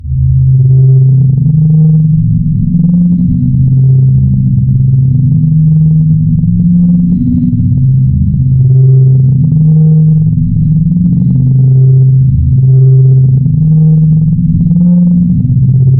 前卫的黑暗爵士乐摇滚乐120 bpm
Tag: 120 bpm Jazz Loops Drum Loops 689.24 KB wav Key : Unknown